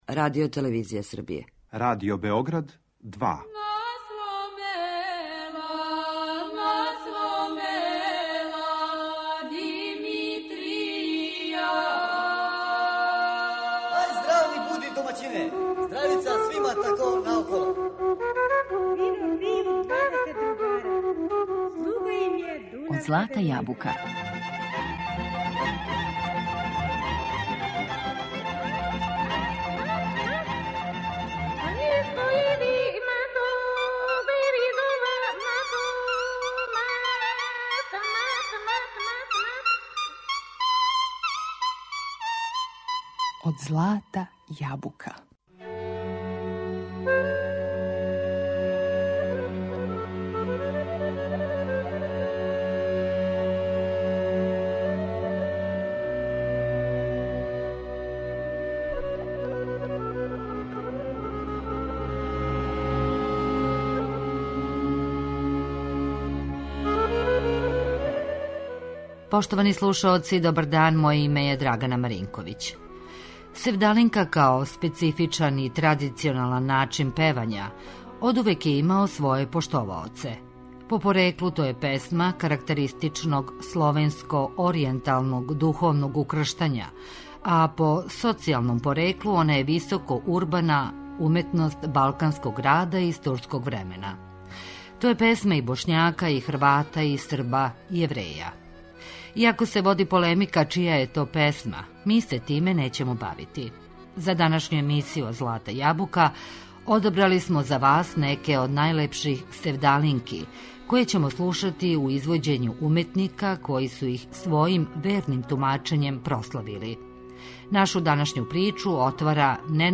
Севдалинке
Севдалинке као специфичан и традиционалан начин певања одувек је имао поштоваоце.
По пореклу, то је песма карактеристично словенско-оријенталног духовног укрштања, а по социјалном, она је високо урбана уметност балканског града из турског времена. У данашњој емисији Од злата јабука одабрали смо неке од најлепших севдалинки, а слушаћемо их у извођењу уметника који су их својим верним тумачењем прославили.